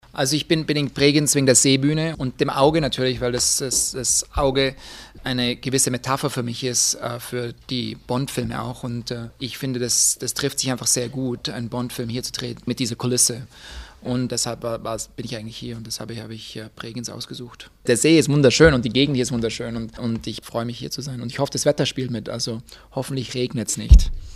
Der unweit von Bregenz bei Ulm geborene Bond-Regisseur meinte am gestrigen Abend kurz vor Drehbeginn: "Das Auge stellt für mich eine gewisse Metapher dar für die Bondfilme.
Interview mit Marc Forster
o-ton_marc_forster.mp3